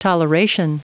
Prononciation du mot toleration en anglais (fichier audio)
Prononciation du mot : toleration